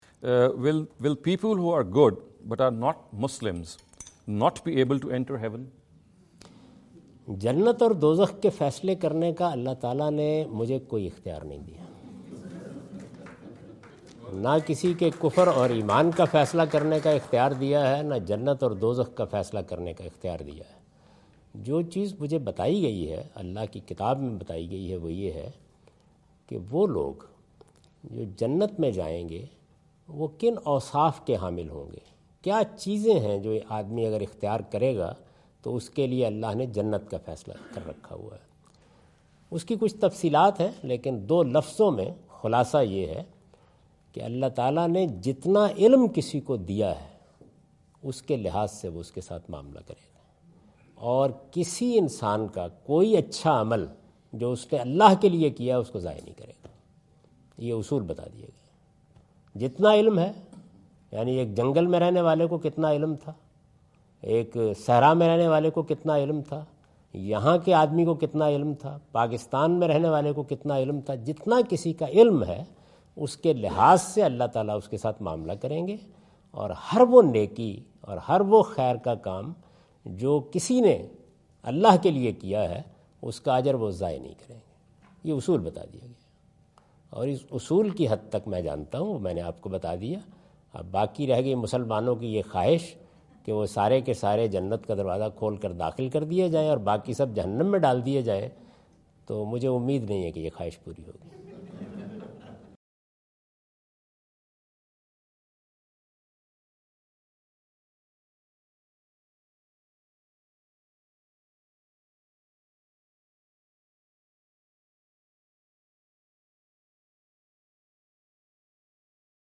In this video Javed Ahmad Ghamidi answer the question about "will pious non-Muslims go to Heaven?" asked at East-West University Chicago on September 24,2017.